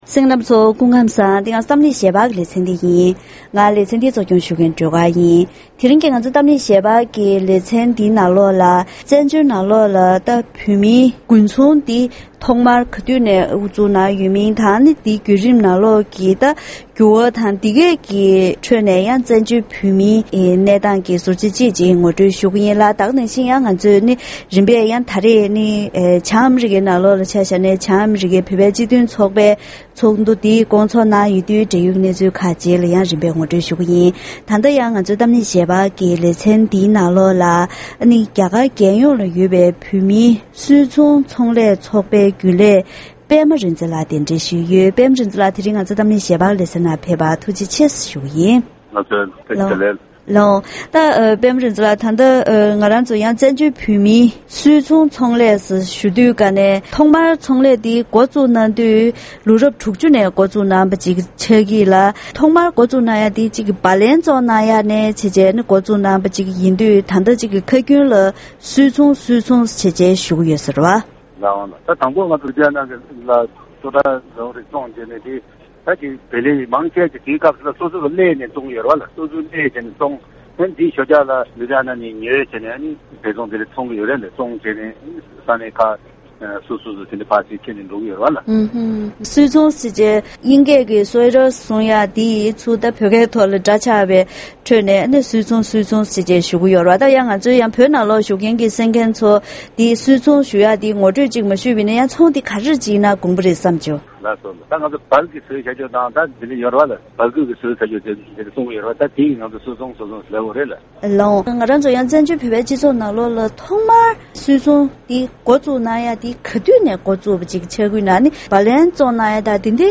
ད་རིང་གི་གཏམ་གླེང་ཞལ་པར་ལེ་ཚན་ནང་བཙན་བྱོལ་བོད་མིའི་ཁྲོད་ཐོག་མར་ལོ་རབས་དྲུག་བཅུ་ནས་དགུན་ཚོང་འགོ་བཙུགས་གནང་བའི་ལོ་རྒྱུས་དང་། ད་ཐེངས་རྒྱ་གར་རྒྱལ་ས་ལྡི་ལིའི་ནང་བོད་པའི་ཚོང་པར་དཀའ་ངལ་འཕྲད་པའི་སྐོར། དེ་བཞིན་བྱང་ཨ་རིའི་བོད་པའི་སྤྱི་མཐུན་ཚོགས་པའི་ཚོགས་འདུའི་གྲོས་ཆོད་ཁག་གི་ཐོག་བཀའ་མོལ་ཞུས་པ་ཞིག་གསན་རོགས་གནང་།